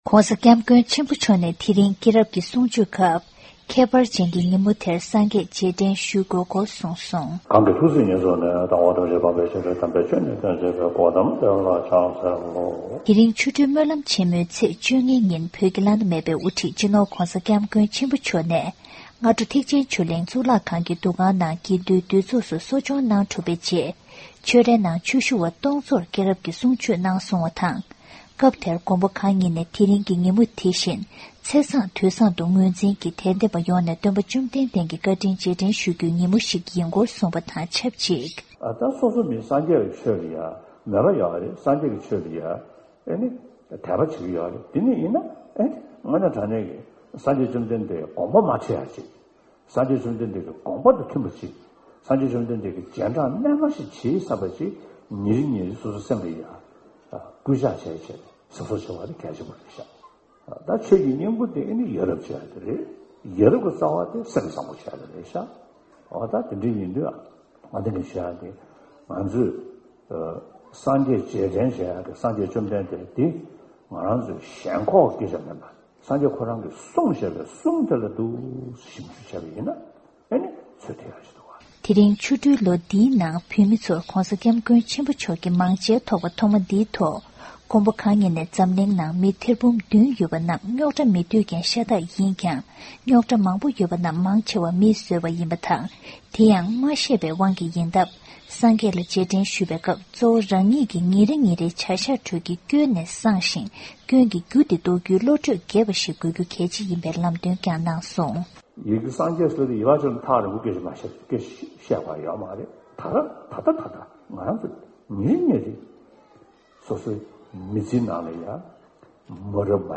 ༧གོང་ས་མཆོག་གིས་ཆོ་འཕྲུལ་སྨོན་ལམ་སྐབས་སྐྱེས་རབས་ཀྱི་གསུང་ཆོས་བསྩལ་བ།